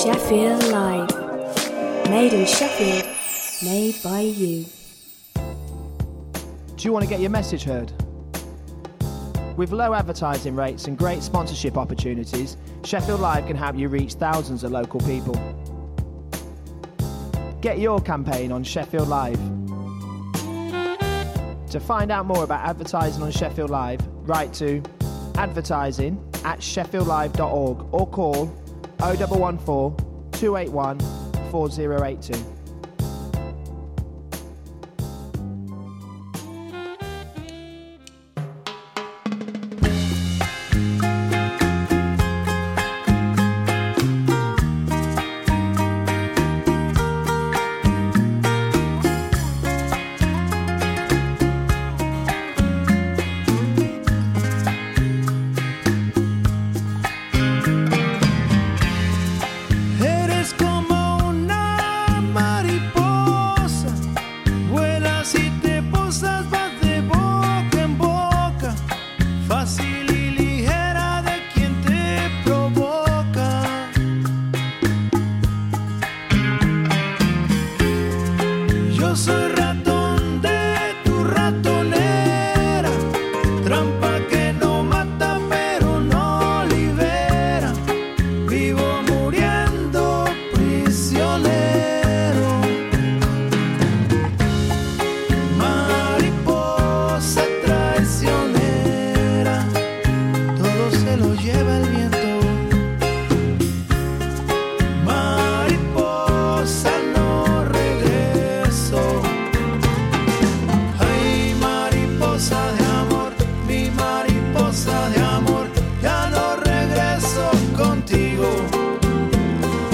Jolly music, news, chat and regular prize giveaways!